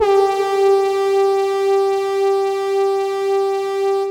brass horn
air brass effect fx horn sound sound effect free sound royalty free Sound Effects